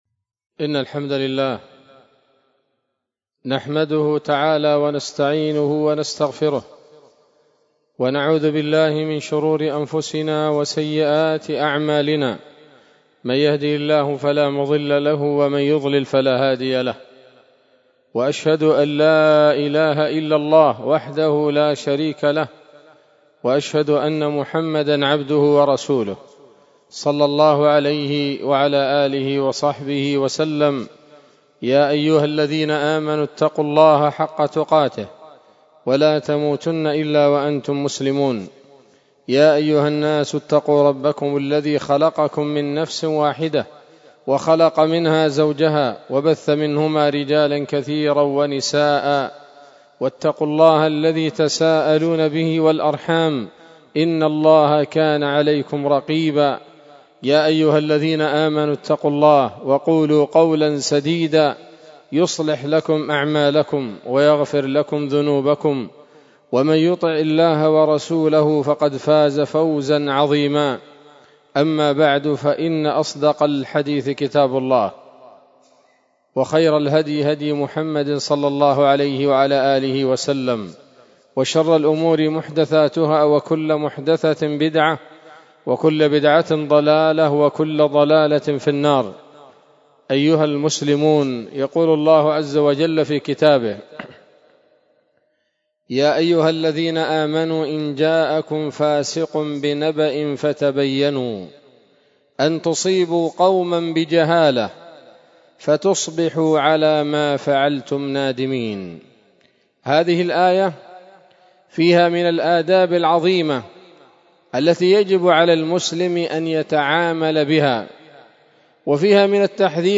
خطبة جمعة بعنوان: (( الأقوال الماتعات في التعامل مع الإشاعات )) 30 جمادى الأولى 1447 هـ، دار الحديث السلفية بصلاح الدين